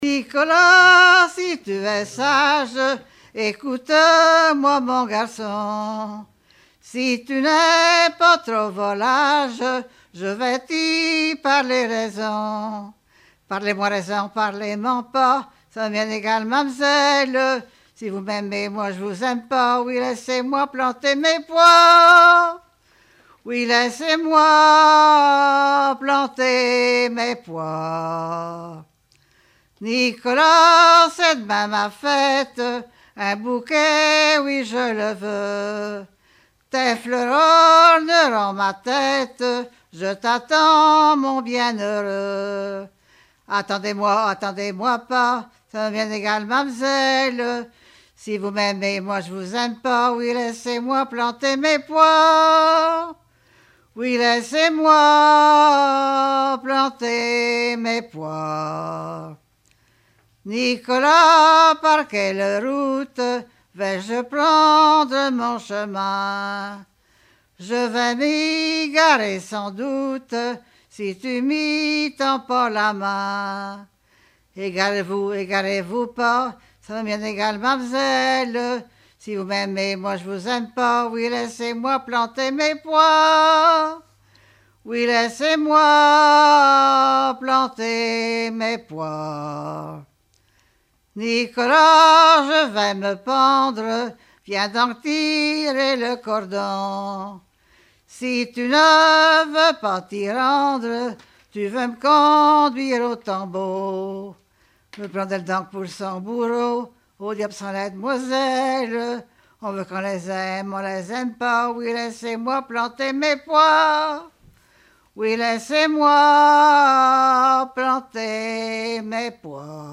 Genre strophique
Regroupement de chanteurs du canton
Pièce musicale inédite